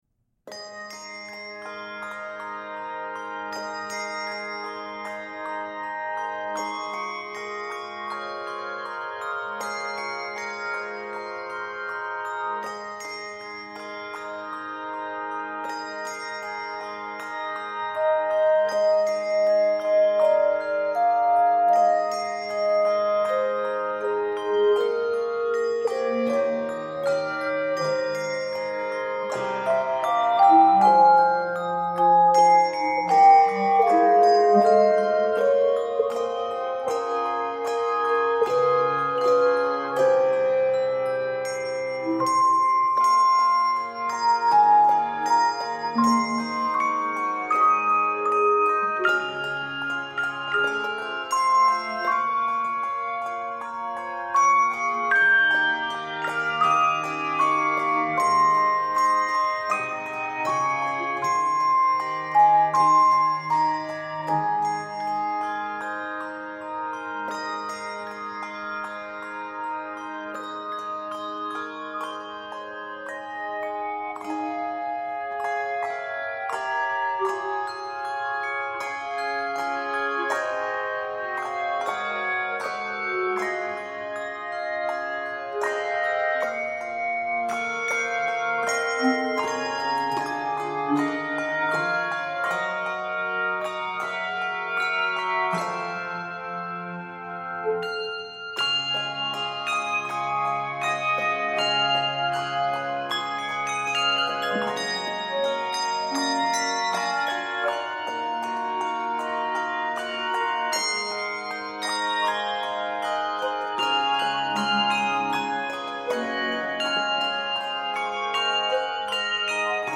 Keys of Db Major and G Major.